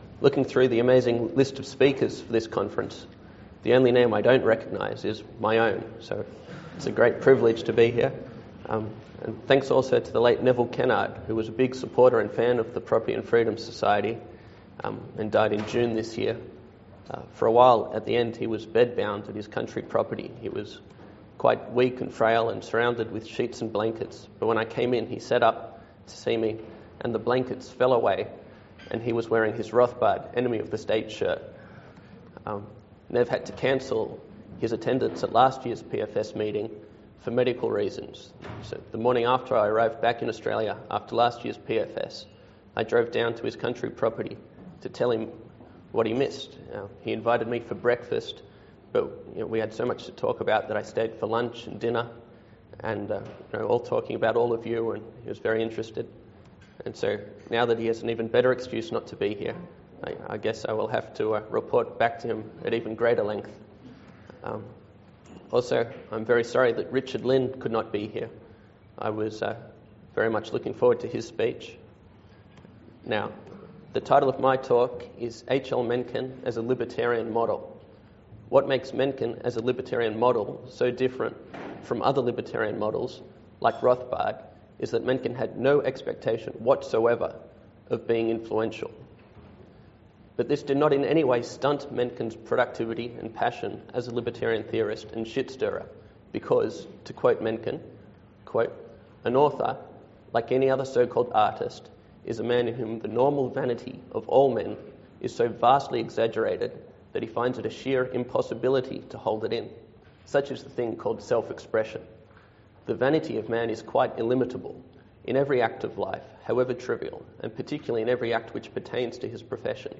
This lecture is from the 2012 meeting of the Property and Freedom Society.